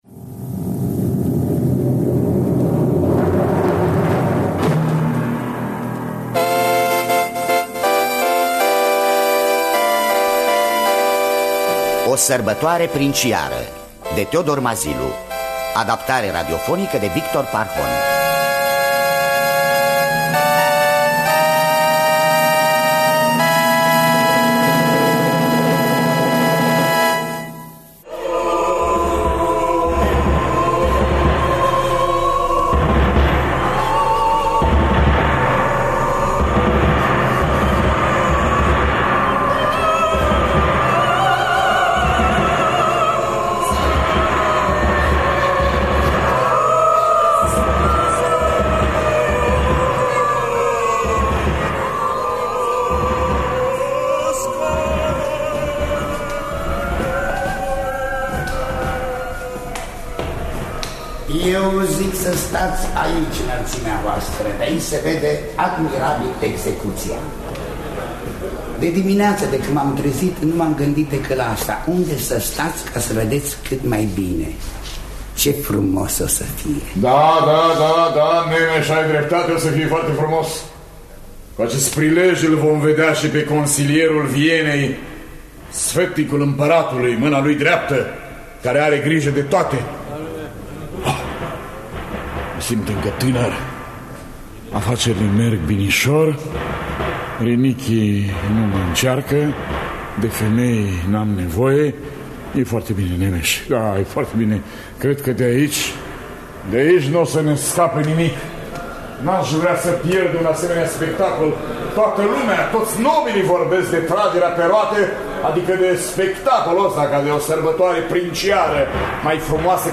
Adaptarea radiofonică de Victor Parhon.